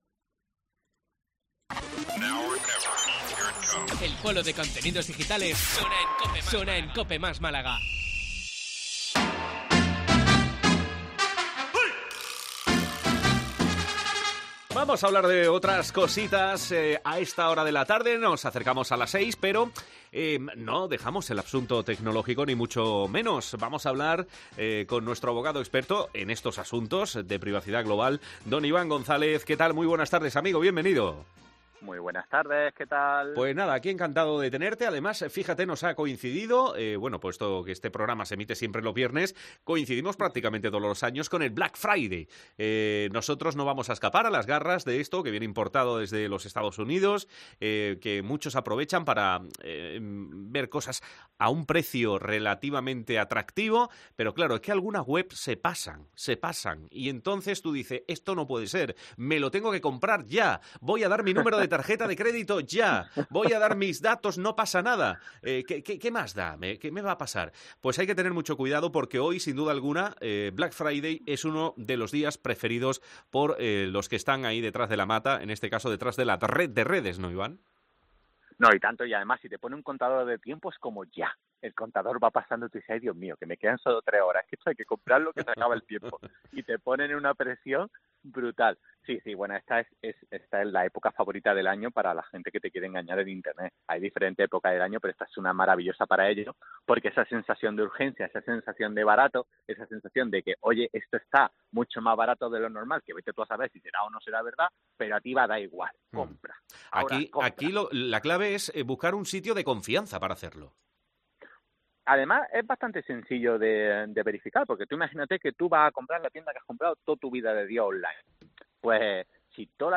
Escucha los consejos de un experto